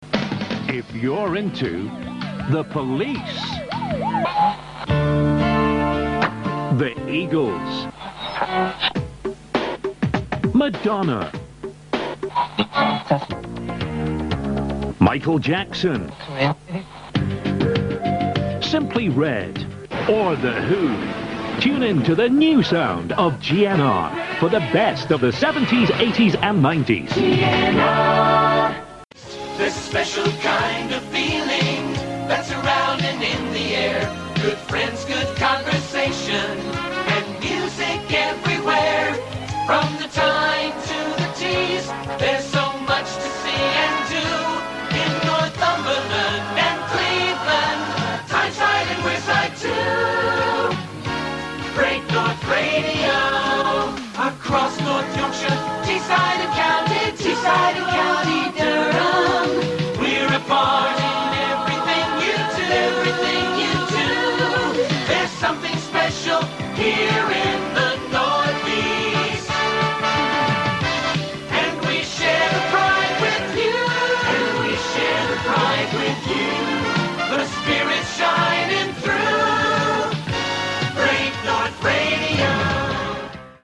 GNR - Ad and ident